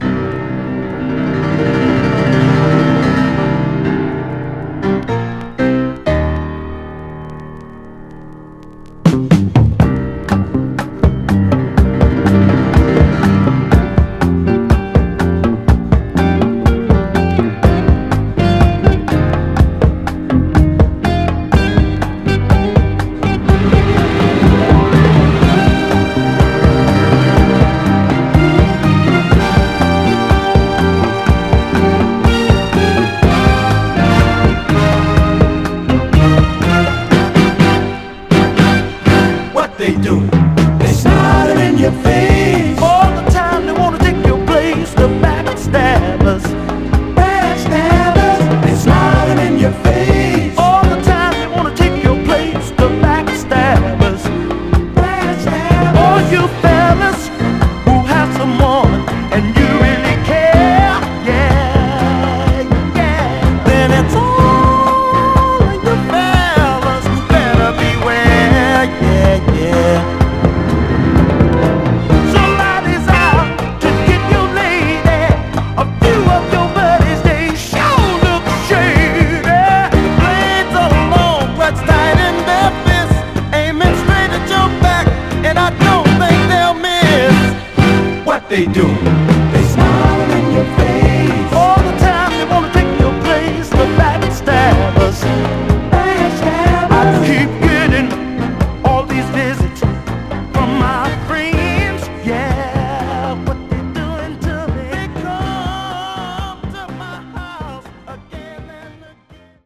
盤は全体的にごく薄いスレありますが、音への影響は少なくプレイ良好です。